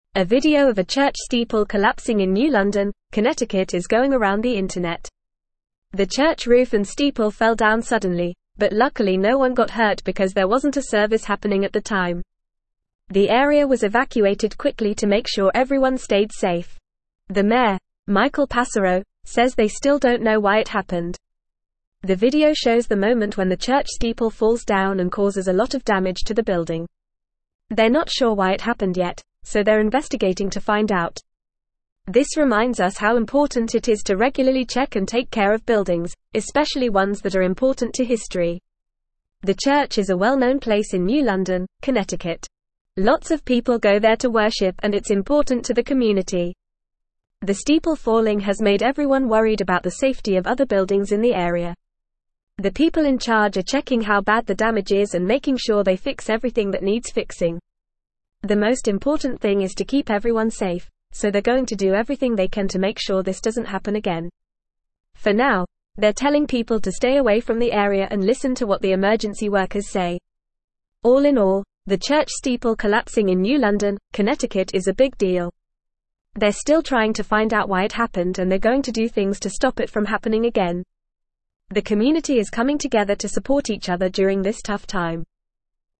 Fast
English-Newsroom-Upper-Intermediate-FAST-Reading-Church-roof-and-steeple-collapse-in-New-London.mp3